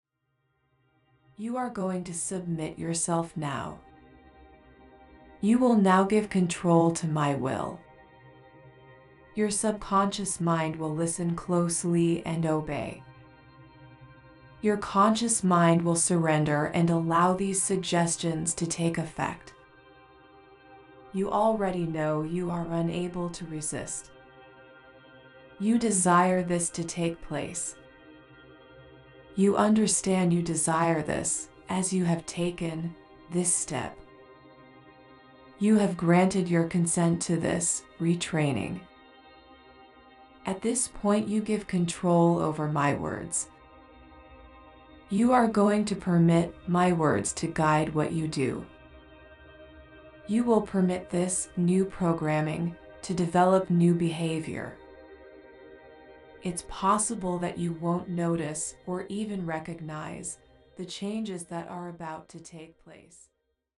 Ultra-Feminine Sample Key Features: Empowering Affirmations: Immerse yourself in positive affirmations that celebrate and amplify your womanly traits.
Non-Intrusive: The hypnosis sessions are gentle, promoting a positive and non-intrusive approach to embracing your ultra-feminine self.